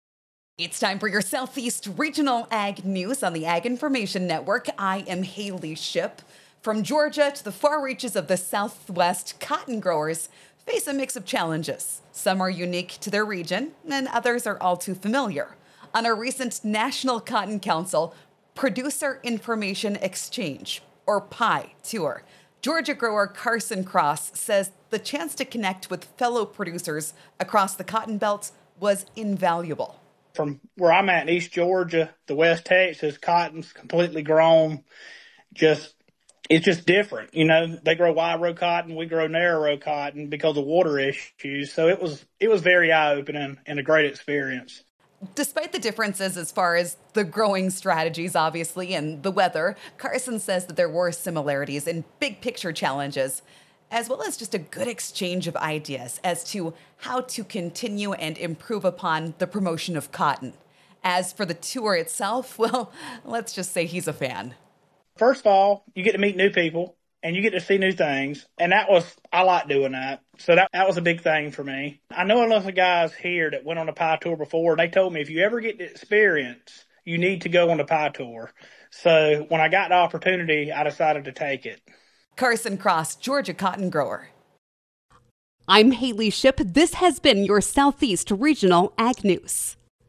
Interview Courtesy of the National Cotton Council of America